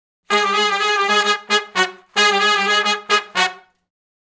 Index of /90_sSampleCDs/Sonic Foundry (Sony Creative Software) - Crimson Blue and Fabulous Horncraft 4 RnB/Horncraft for R&B/Sections/011 Funk Riff
011 Funk Riff (F#) unison.wav